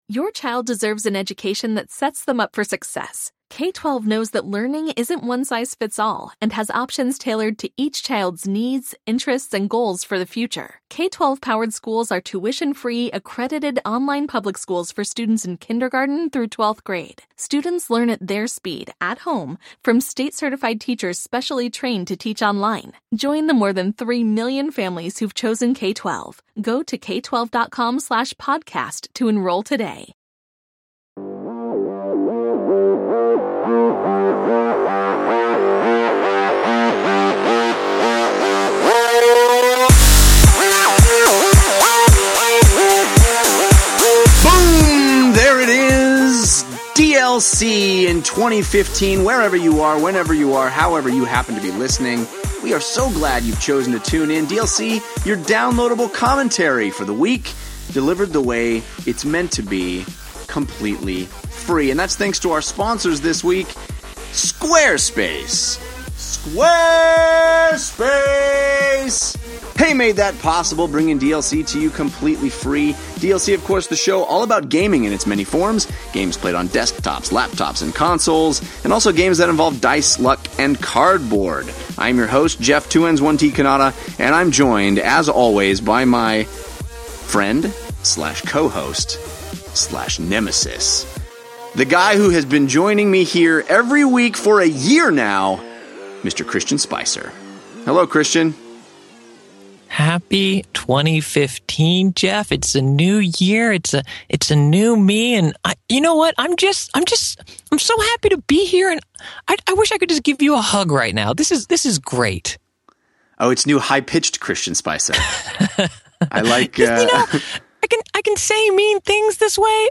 All that, plus YOUR phone calls!